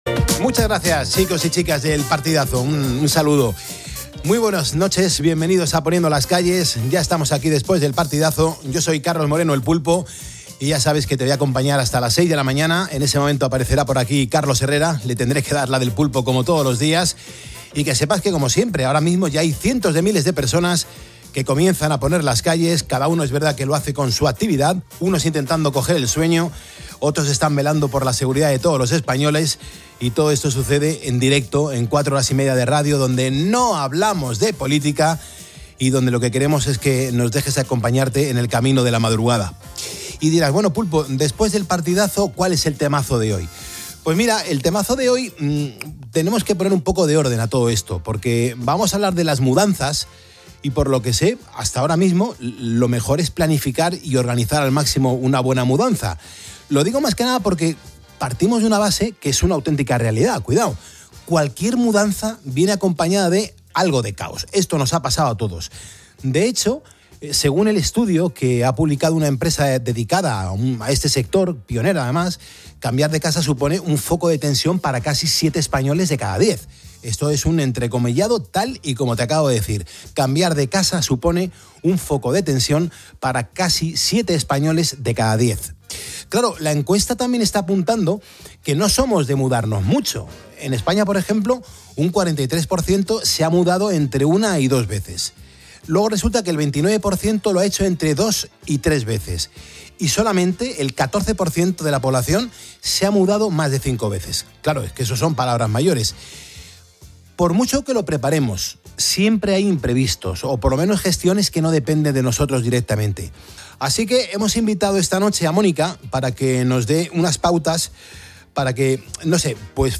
entrveista